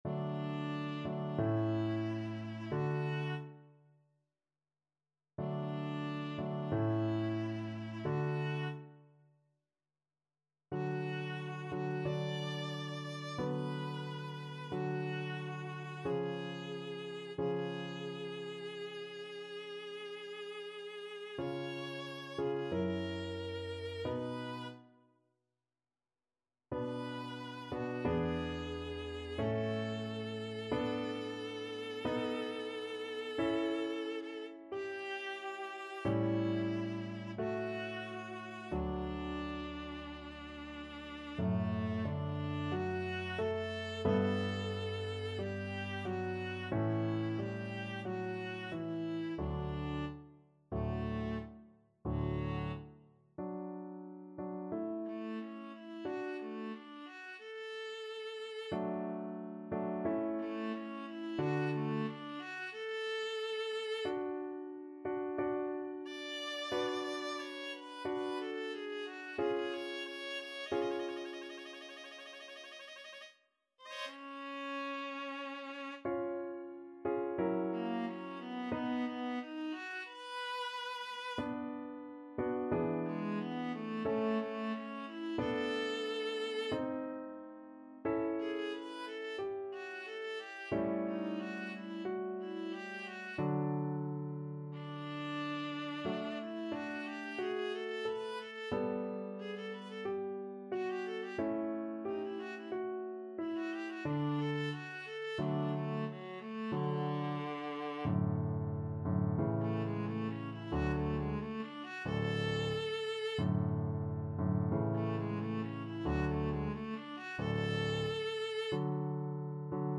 Viola version
4/4 (View more 4/4 Music)
Larghetto =c.45
D4-B6
Classical (View more Classical Viola Music)